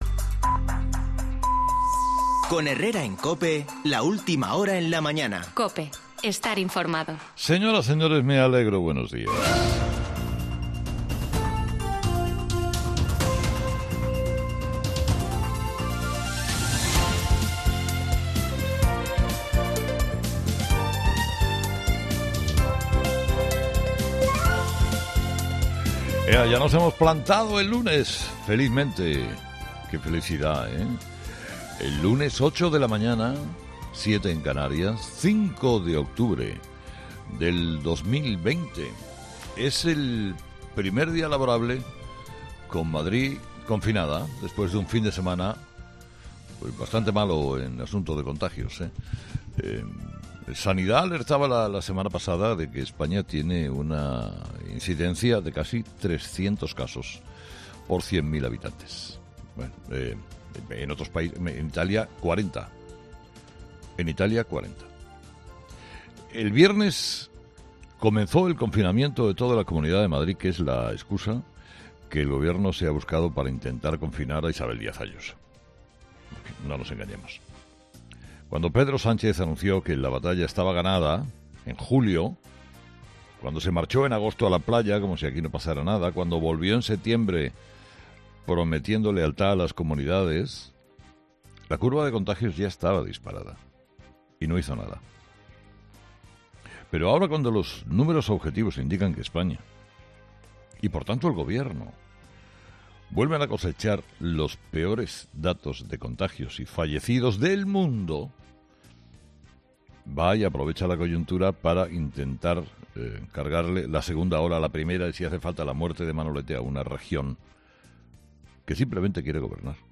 ESCUCHA AQUÍ EL EDITORIAL DE HERRERA En fin, las cifras de Mafdrid no son buenas porque las de España no lo son.